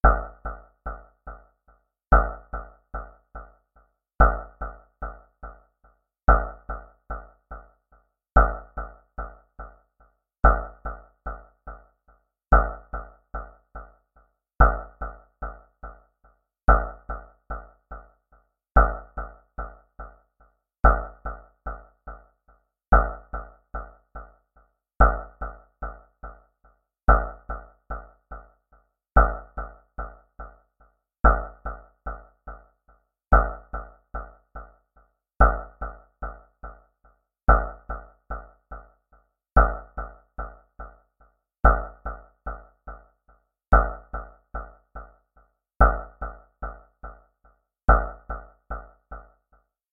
Звуки ожидания
Напряженный момент обратного отсчета